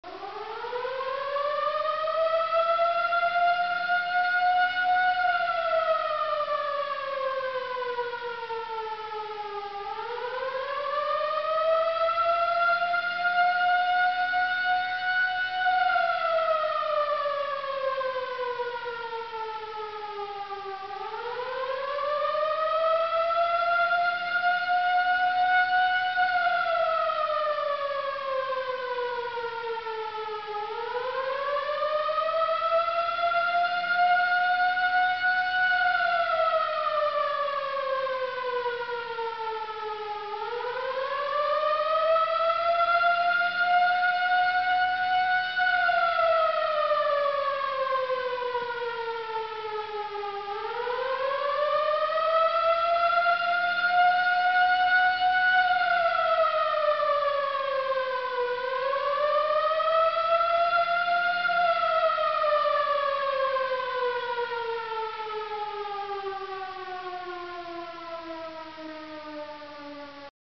Das Signal bei Katastrophenalarm
warnsirene.mp3